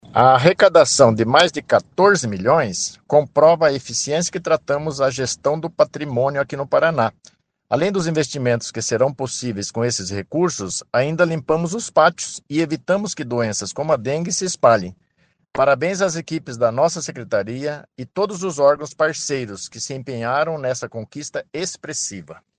Sonora do secretário Estadual da Administração e Previdência, Luizão Goulart, sobre a arrecadação recorde em leilões de veículos em 2025